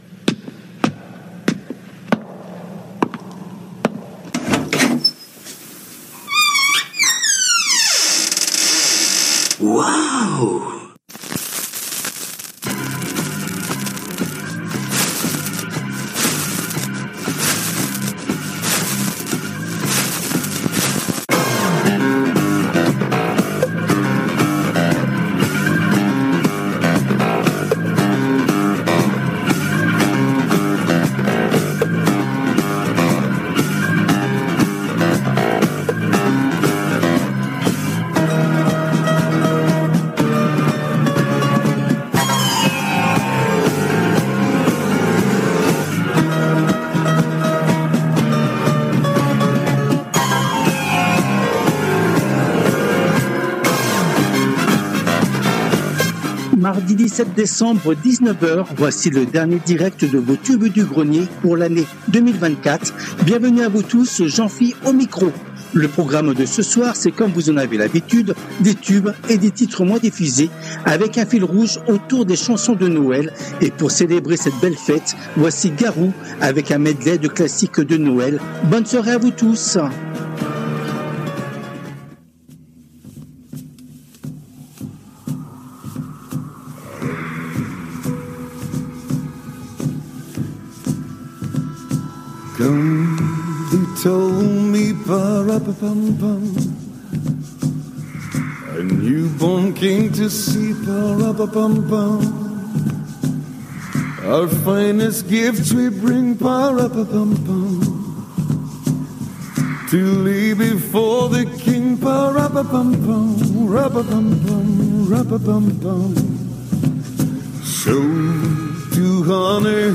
Les Tubes connus ou oubliés des 60's, 70's et 80's